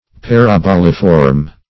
Search Result for " paraboliform" : The Collaborative International Dictionary of English v.0.48: Paraboliform \Par`a*bol"i*form\ (p[a^]r`[.a]*b[o^]l"[i^]*f[^o]rm), a. [Parabola + -form.] Resembling a parabola in form.